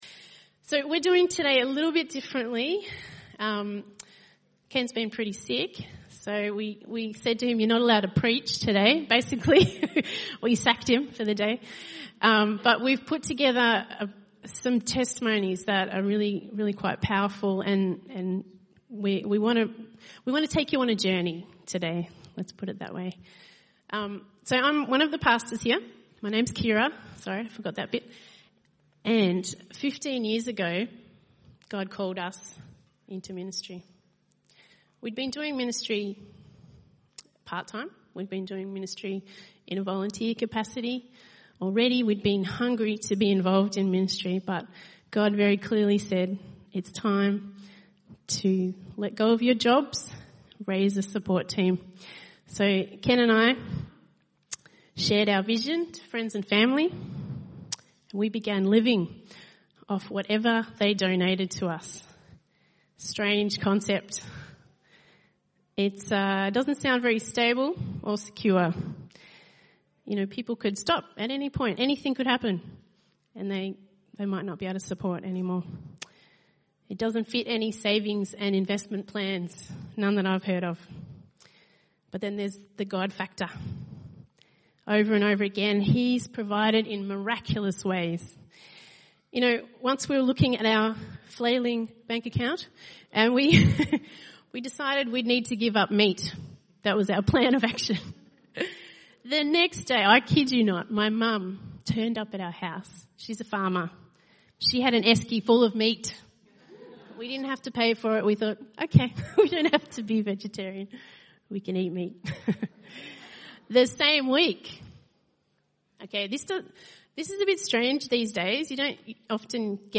by enmelbourne | Nov 8, 2019 | ENM Sermon